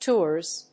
/tʊrz(米国英語), tɔ:rz(英国英語)/